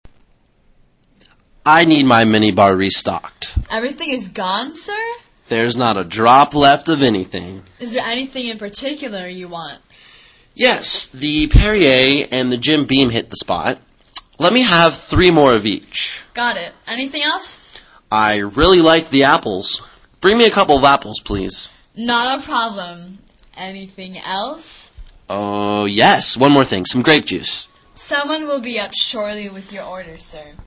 旅馆英语对话-The Minibar(2) 听力文件下载—在线英语听力室